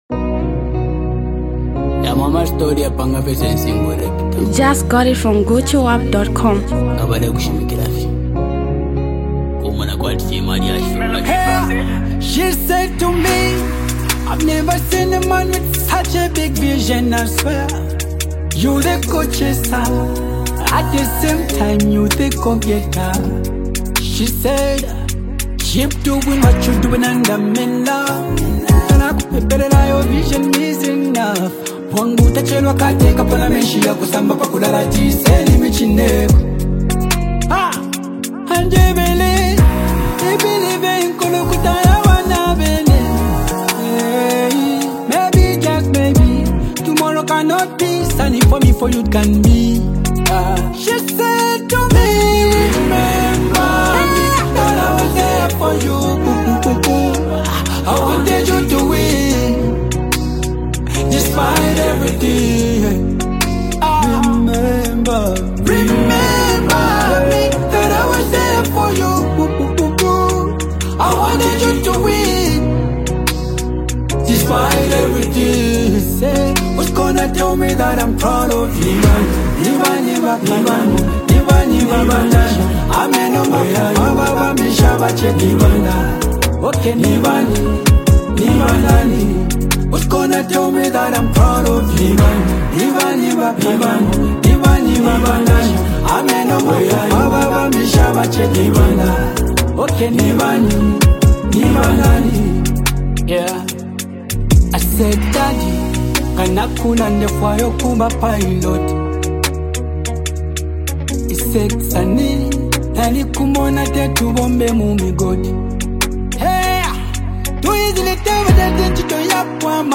a zambian prominent up talented singer and songwriter
It’s a heartfelt gratitude journal song